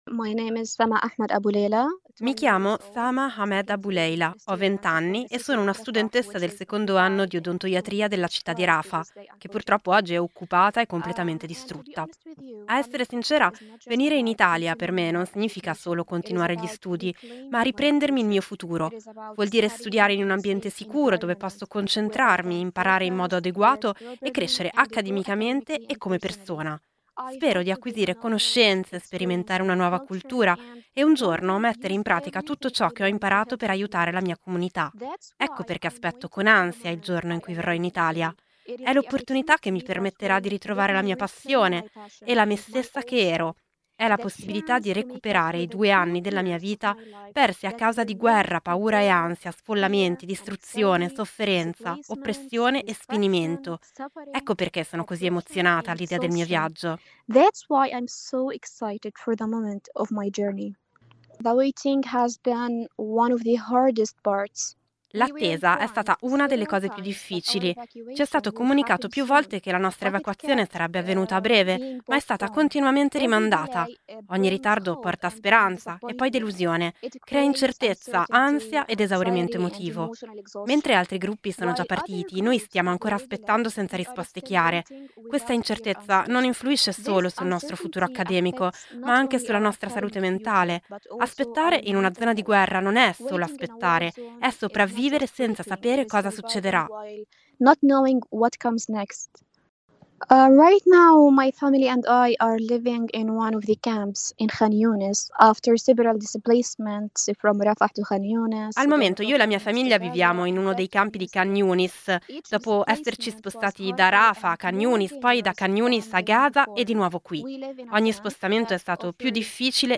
Come sentirete dalle loro voci, ci sperano ancora e fanno di tutto per essere pronti.
Intervista